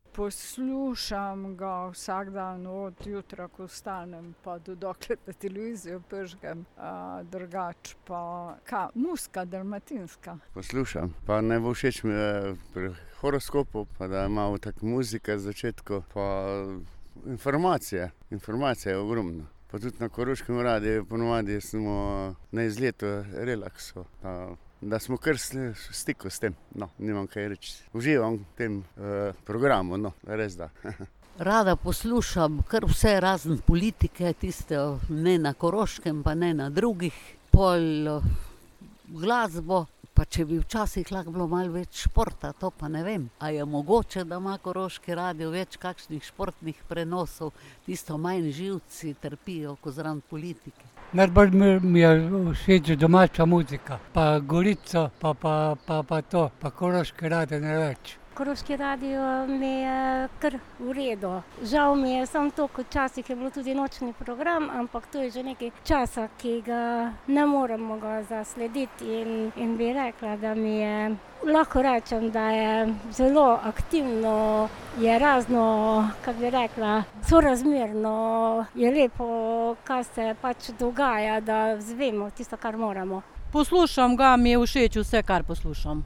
06.30 Prisluhnite, kaj so ob tej priložnosti o nas povedali naključni mimoidoči na Prevaljah: